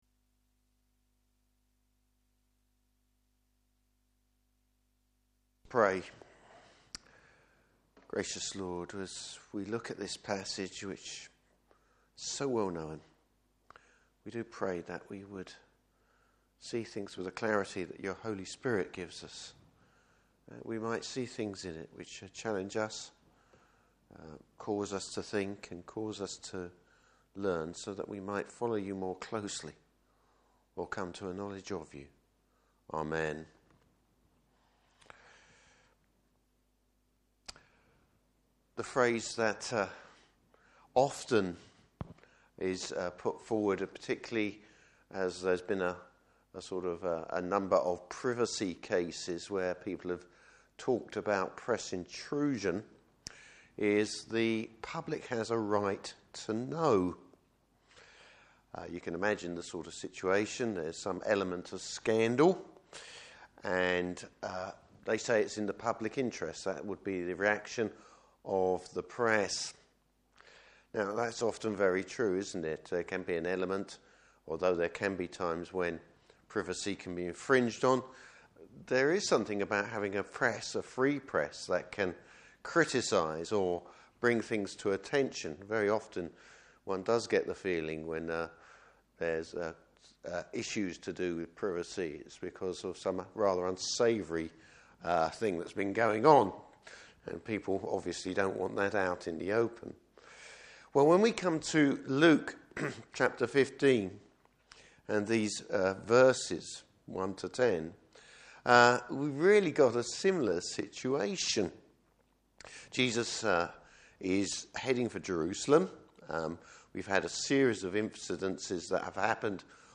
Service Type: Morning Service Bible Text: Luke 15:1-10.